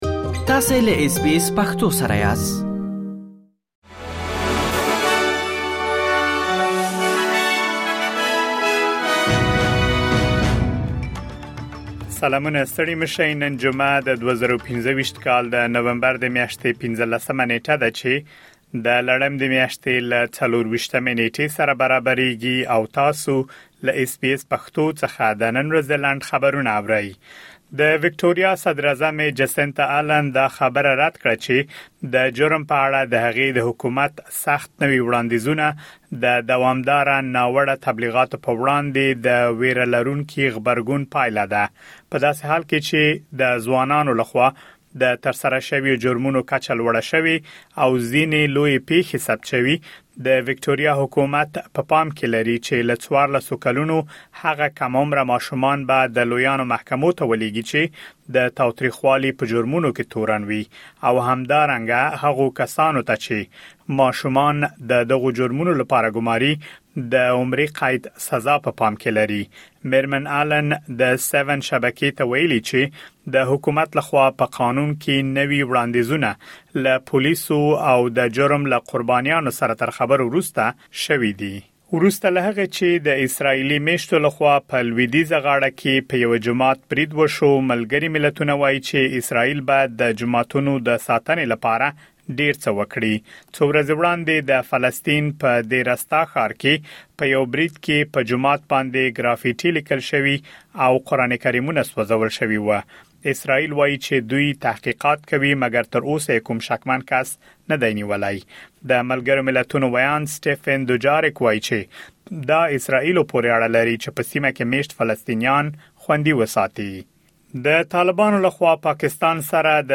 د اس بي اس پښتو د نن ورځې لنډ خبرونه |۱۴ نومبر ۲۰۲۵
د اس بي اس پښتو د نن ورځې لنډ خبرونه دلته واورئ.